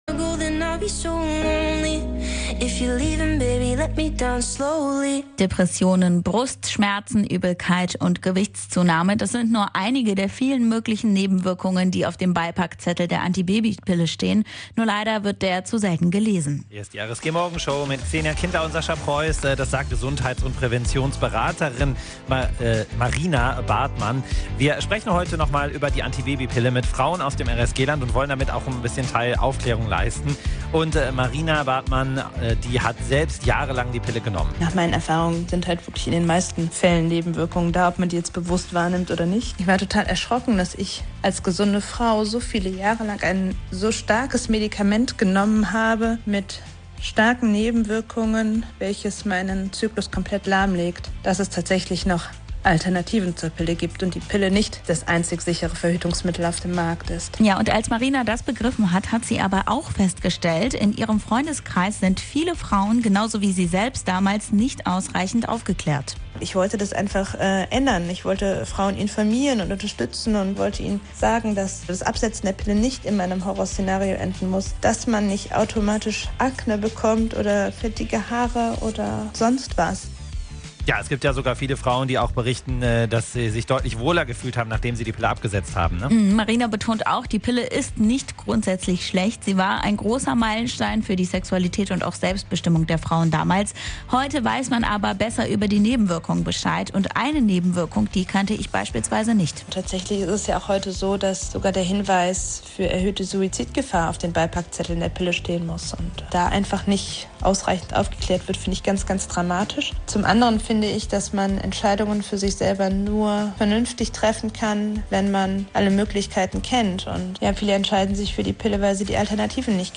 Wir haben mit Frauen aus dem RSG-Land über die Pille und alternative Verhütungsmethoden gesprochen.